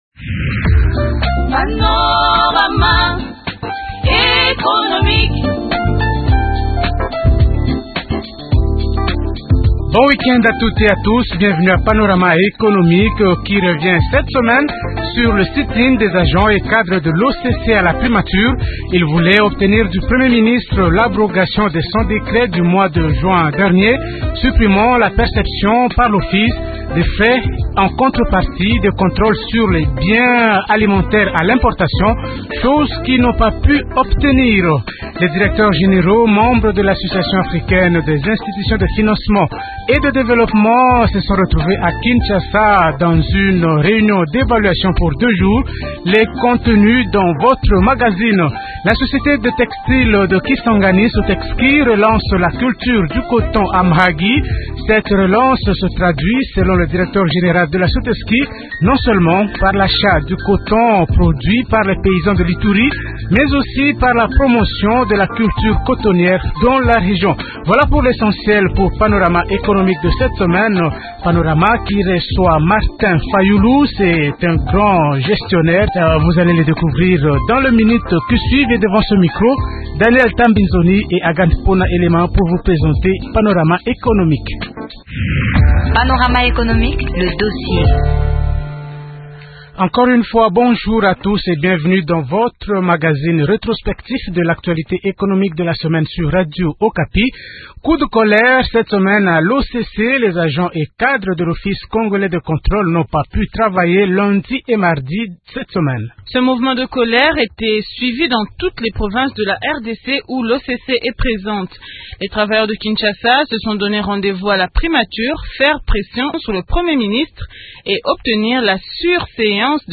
Le magazine “Panorama économique” a reçu samedi 12 novembre l’opérateur économique Martin Fayulu. Il a commenté les grands sujets qui ont dominé l’actualité économique de la semaine.